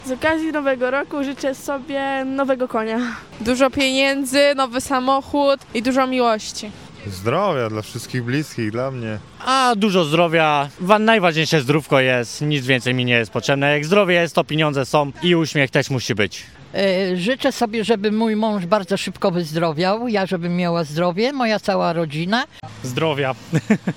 Zapytaliśmy mieszkańców Stargardu, czego życzą sobie w nadchodzącym roku.